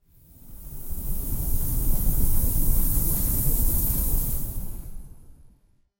Hot desert wind sweeping across sand dunes with a dry, whistling tone
desert-wind.mp3